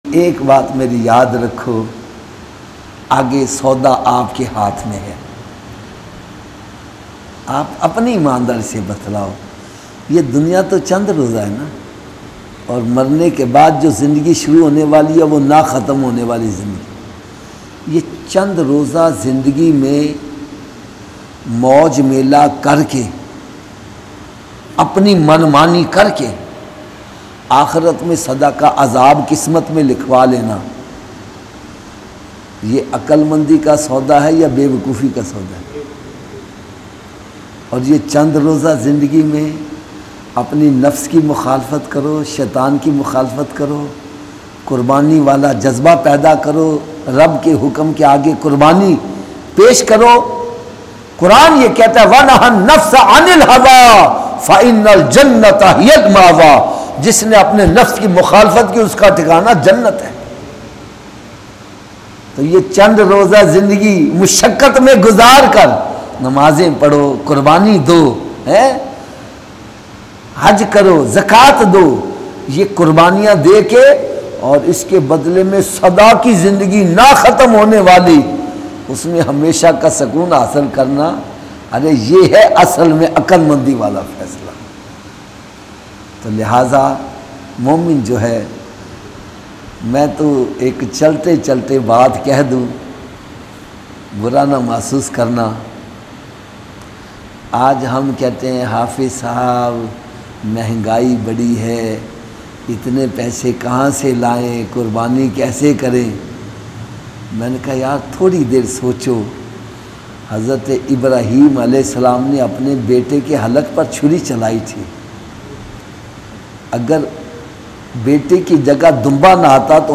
Qurbani Kesy Karen Cryful Bayan MP3 Download
Qurbani-kesy-karen-Dardnak Bayan.mp3